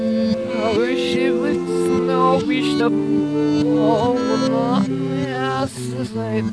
Backwards